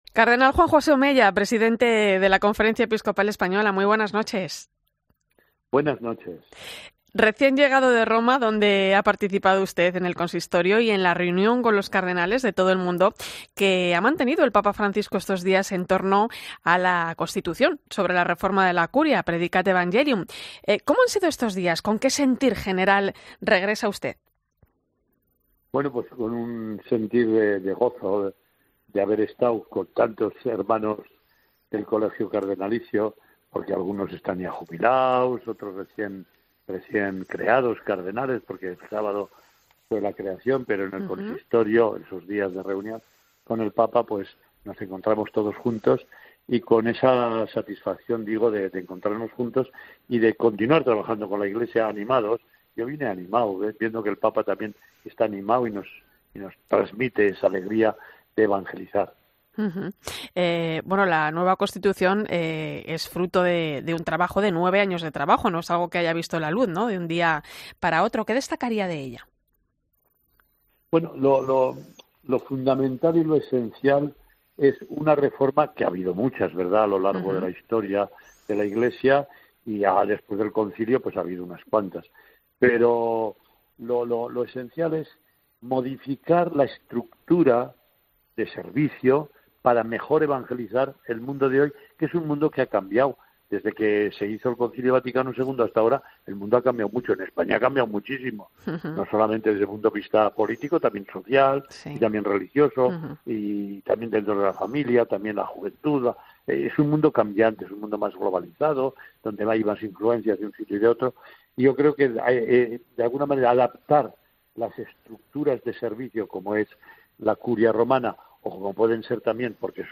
El arzobispo de Barcelona ha explicado en "La Linterna de la Iglesia" de COPE los puntos clave del encuentro de cardenales en Roma: "La clave es la sinodalidad"